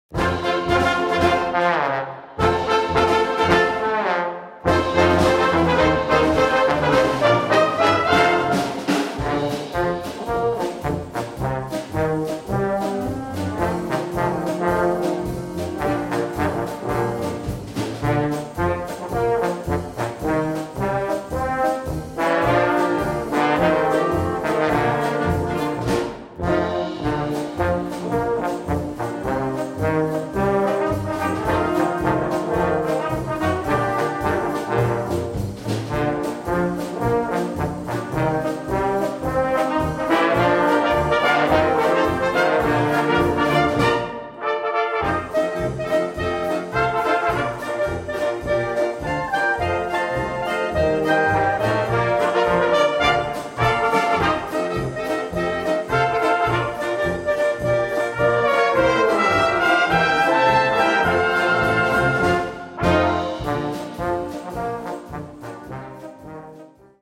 Ein spritziger, flotter Fox für großes Blasorchester
3:34 Minuten Besetzung: Blasorchester Tonprobe